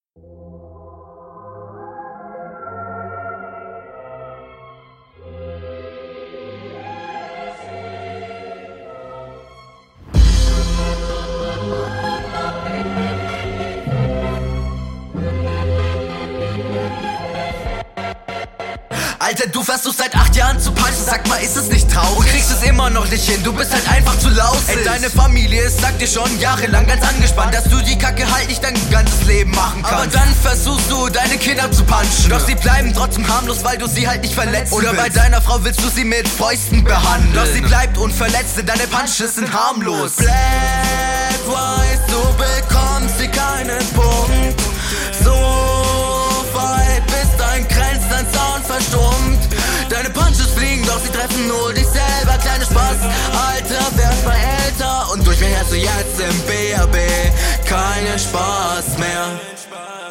Sound wieder gut.